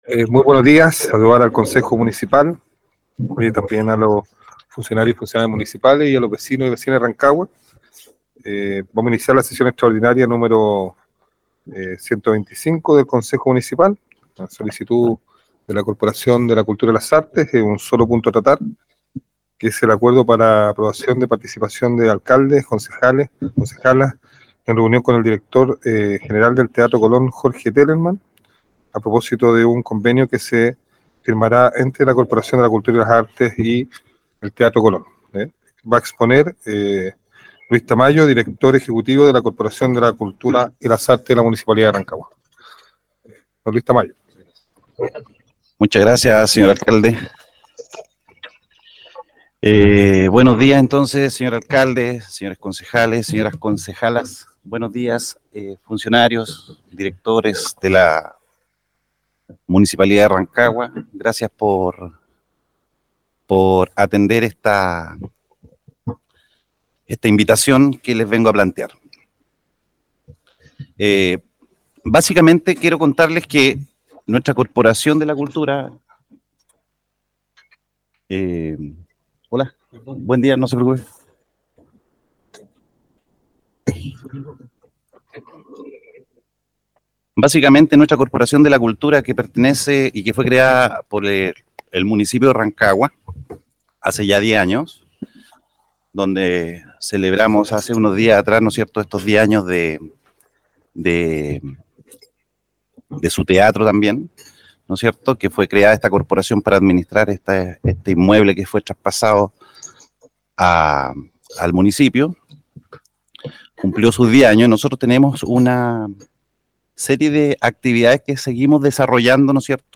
Sesión del Concejo Municipal Extraordinario número 125, efectuado el viernes 21 de julio de 2023.